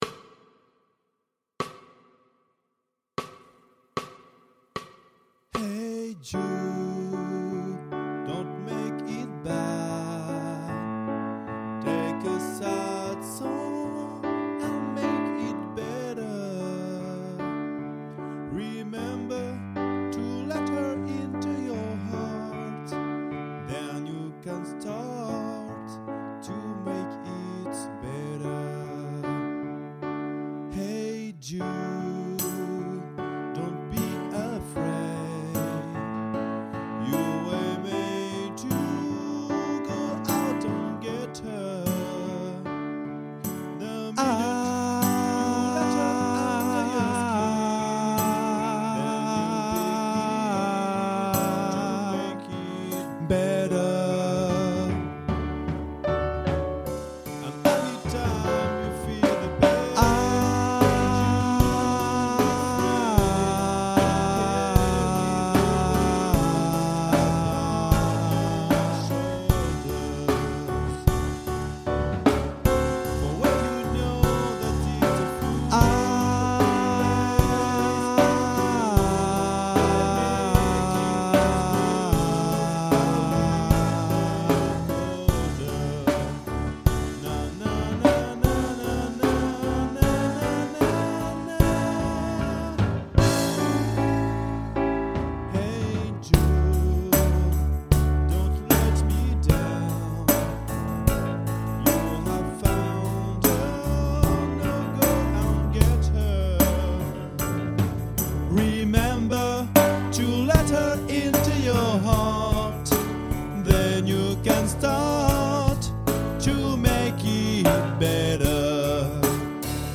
Vous trouverez ici les voix enregistrées à télécharger au format MP3 pour vous aider à apprendre votre voix avec les paroles.
Soprano 1